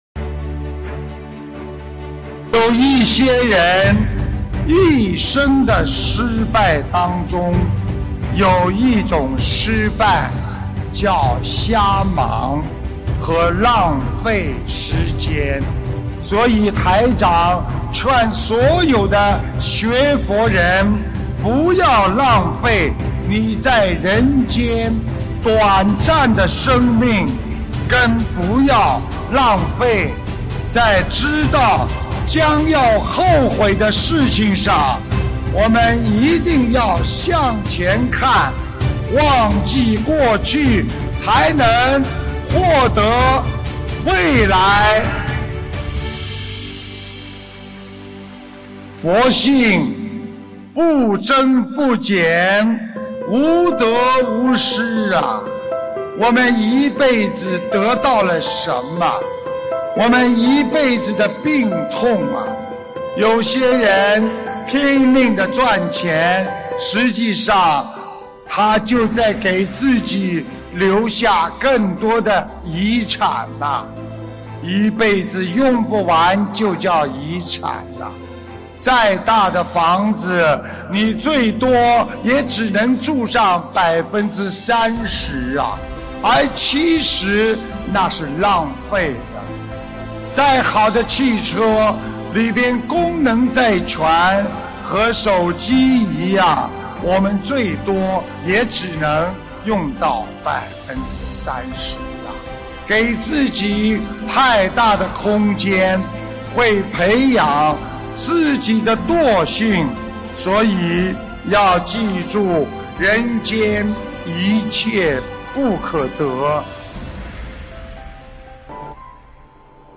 —— 2015年1月24日 马来西亚 槟城法会开示